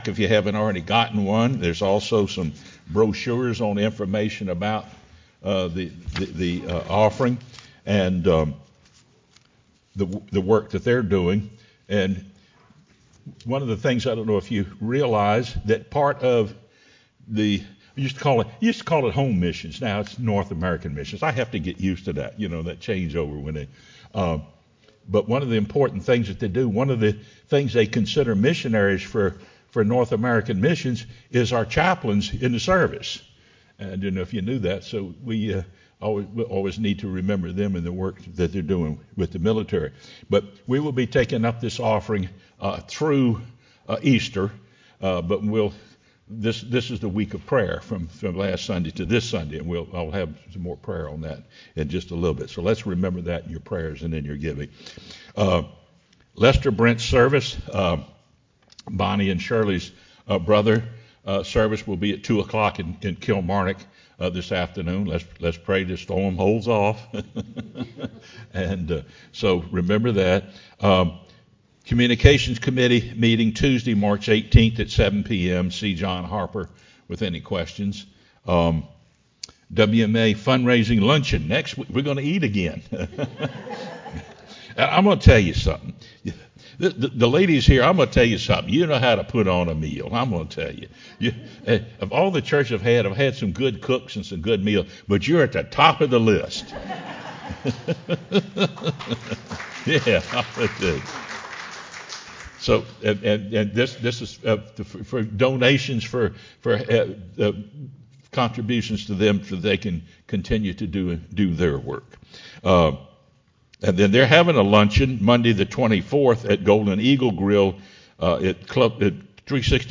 sermonMar16-CD.mp3